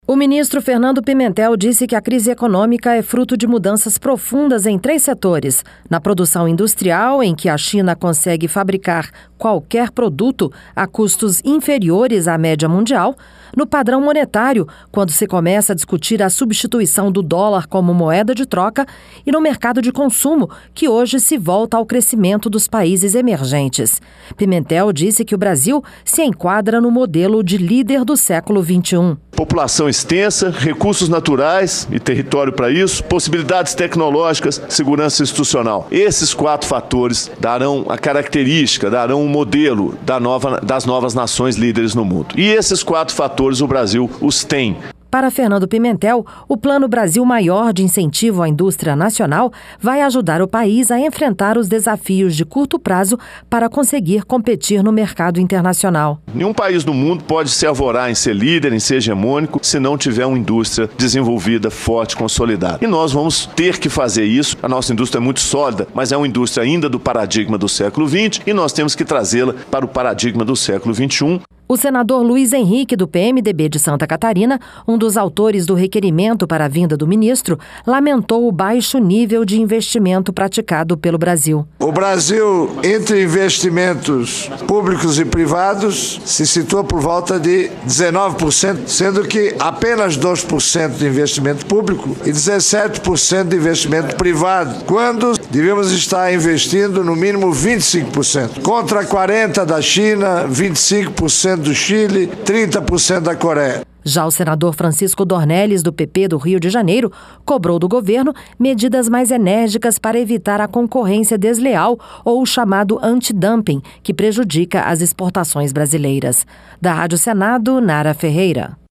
LOC: EM AUDIÊNCIA DA COMISSÃO DE ASSUNTOS ECONÔMICOS, PIMENTEL AFIRMOU QUE O PLANO "BRASIL MAIOR" DE INCENTIVO À INDUSTRIA NACIONAL PODE AJUDAR O PAÍS A ENFRENTAR A PESADA CONCORRÊNCIA NO MERCADO MUNDIAL.